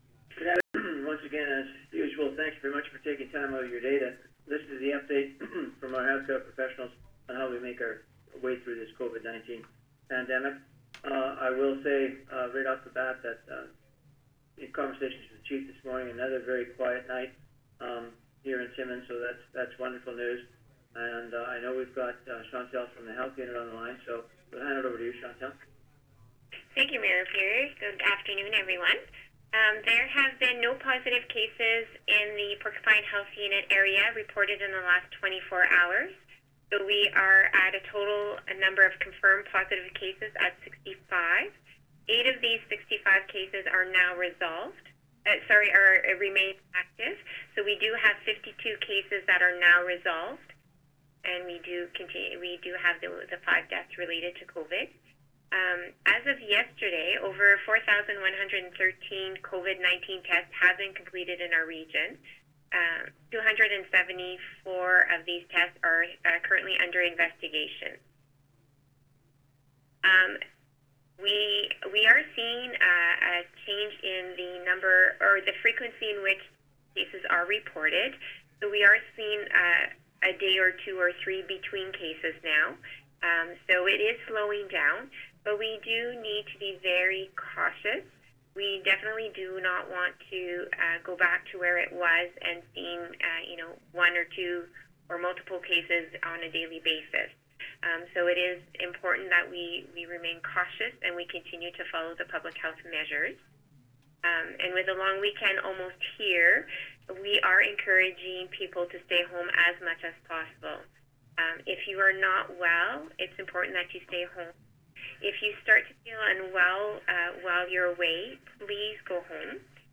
Every day at noon during the COVID-19 pandemic, Timmins Mayor George Pirie chairs a conference call of his Health Table, to update the pandemic situation.
Here is raw audio of today’s call.